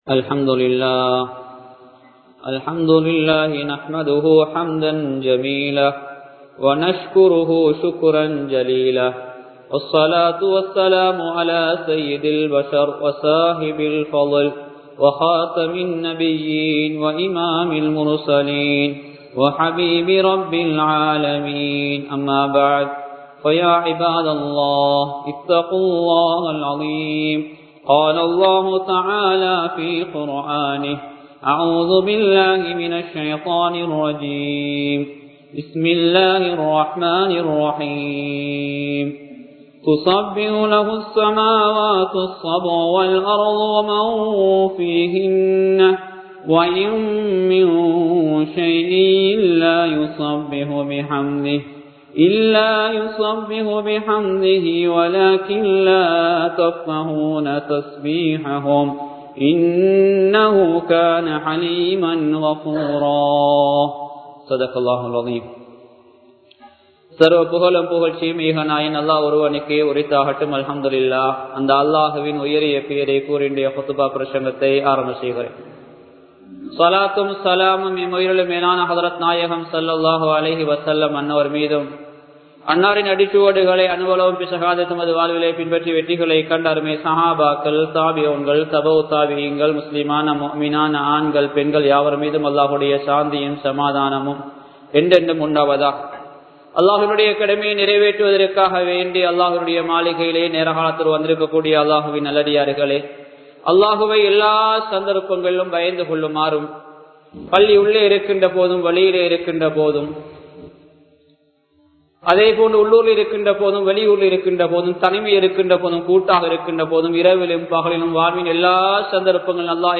படைத்தவனுக்கு நன்றி செலுத்துங்கள் | Audio Bayans | All Ceylon Muslim Youth Community | Addalaichenai
Kurunagela, Pannawa Jumuah Masjith